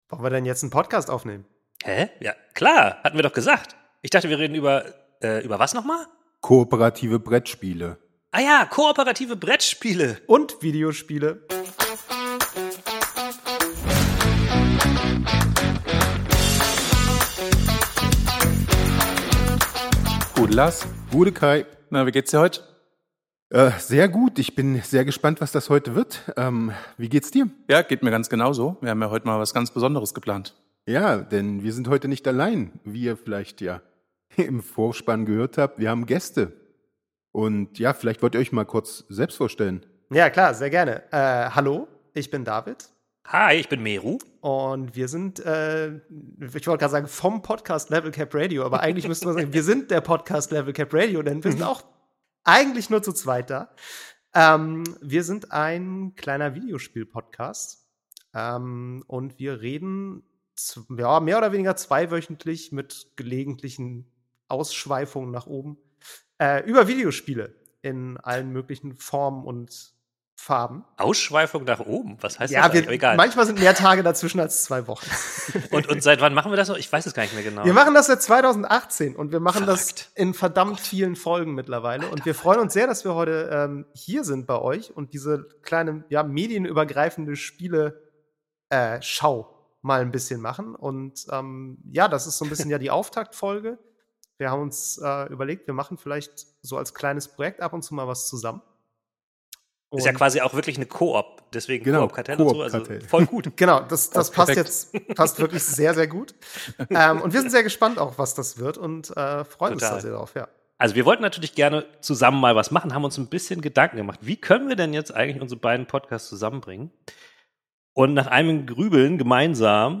– mit überraschenden Erkenntnissen, viel Gelächter und vielleicht der ein oder anderen neuen Lieblings-Empfehlung!